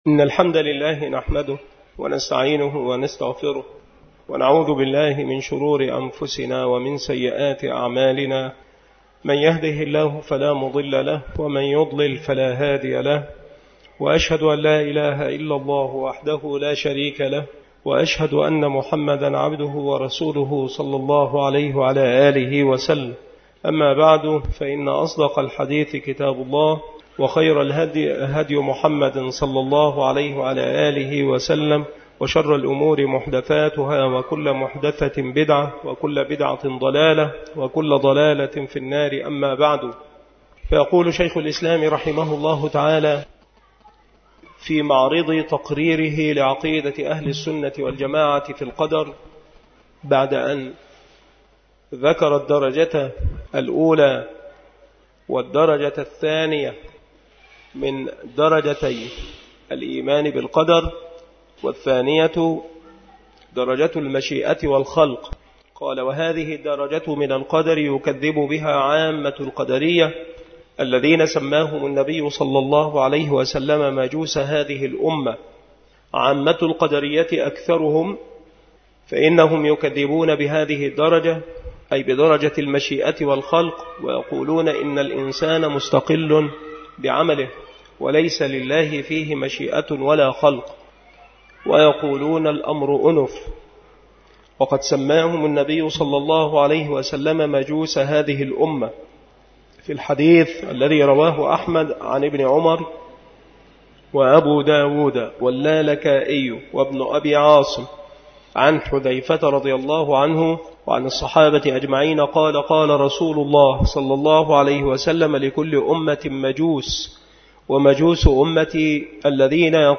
المحاضرة
بالمسجد الشرقي بسبك الأحد - أشمون - محافظة المنوفية - مصر